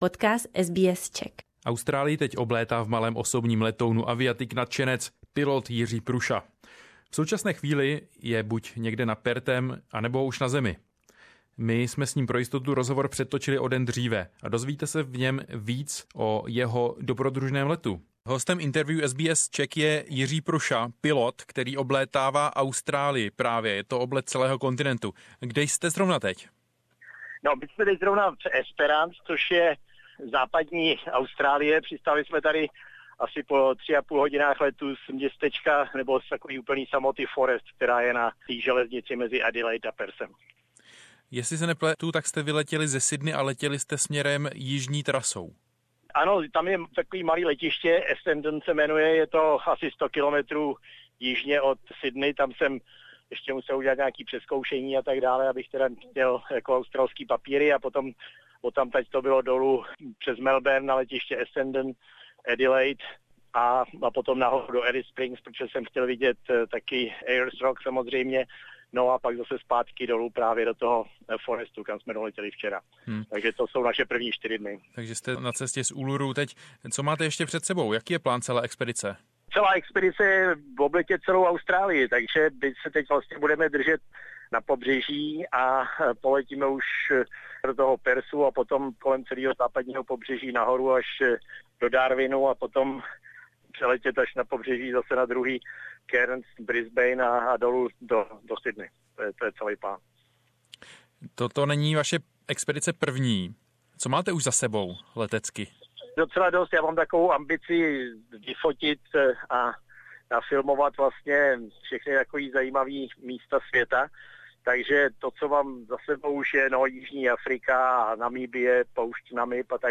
He pilots a small four seater alone. He says, when he likes a place he just lands. Now, he is in Interview SBS Czech.